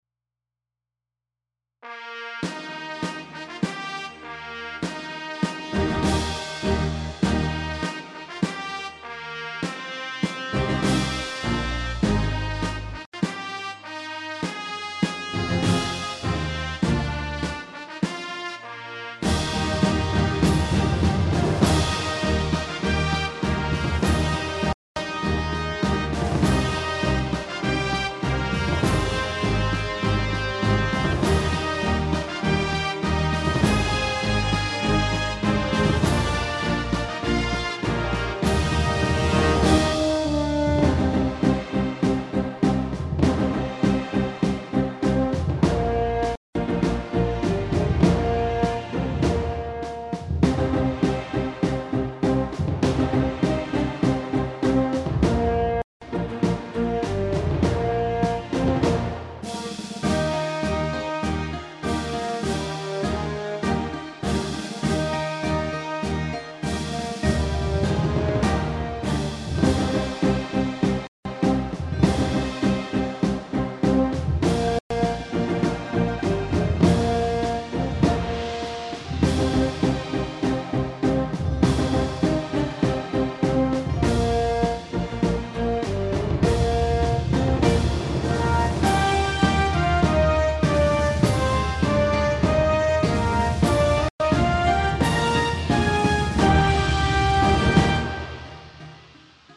מצעד יווני- עזרתכם בבקשה
אני מנגנת כרגע קטע של מצעד שאמור להמחיש את חיילי יוון העתיקה.
והנה הוא- המצעד לא מאוד יווני פשוט כי לא התאים להם מצעד רק של תופים וצעקות...